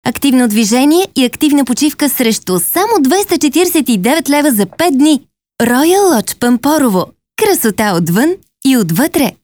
Professionelle Sprecherin bulgarisch für TV / Rundfunk / Industrie.
Kein Dialekt
Sprechprobe: eLearning (Muttersprache):
bulgarian female voice over artist